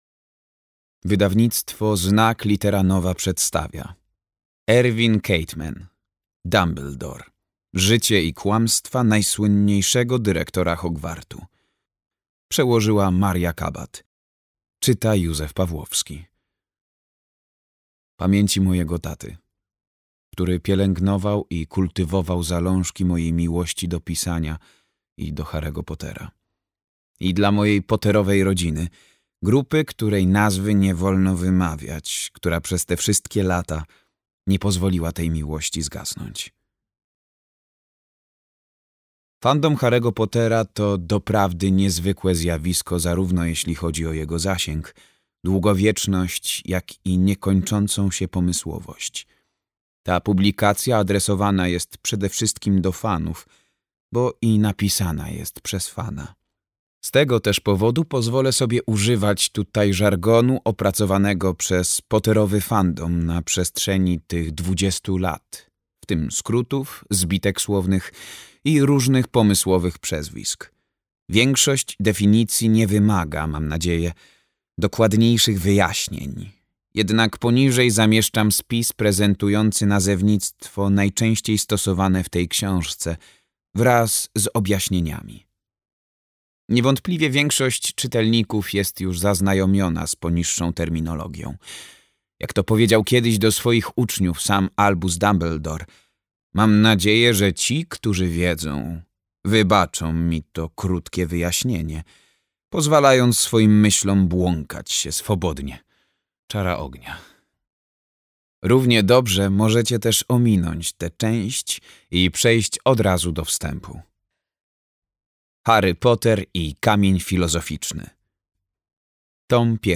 Dumbledore. Życie i kłamstwa najsłynniejszego dyrektora Hogwartu - Khaytman Irvin - audiobook